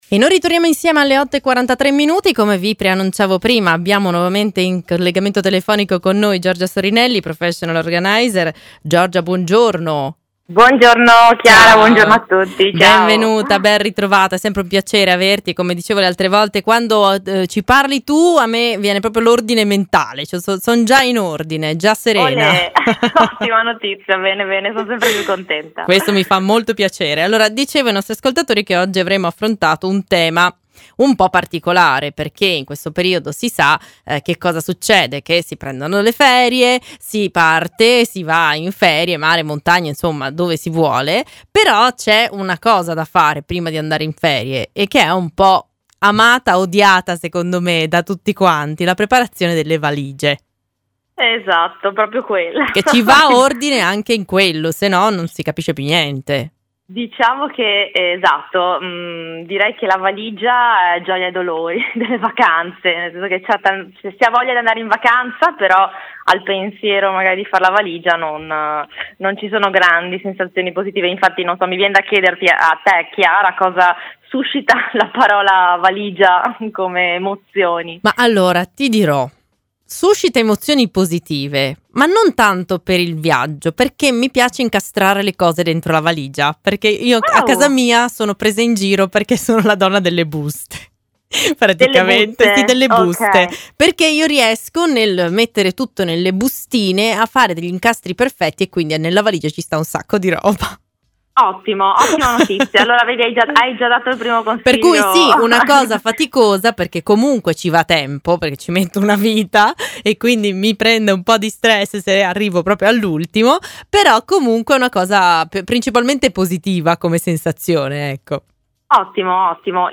INTERVISTA-PROFESSIONAL-ORGANIZER-VALIGIE.mp3